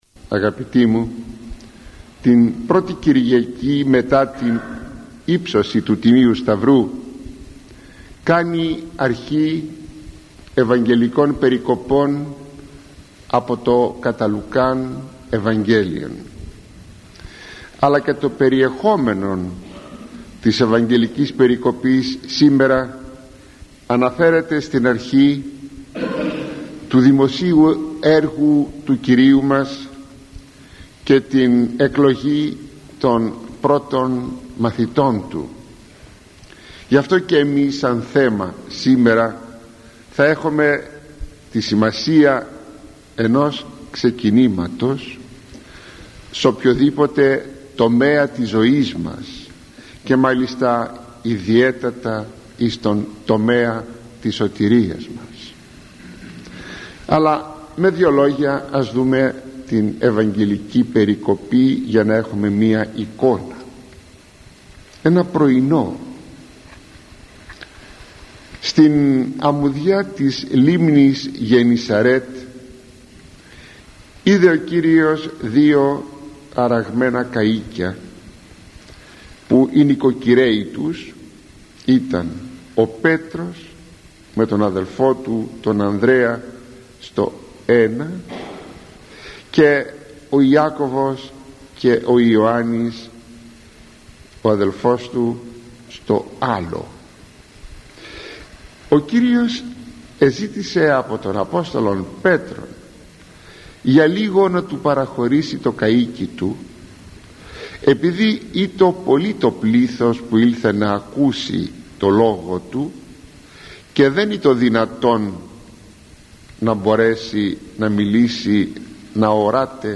Κυριακή Α. Λουκά: “η συμασία της αρχής” – ηχογραφημένη ομιλία του Μακαριστού Αρχιμ.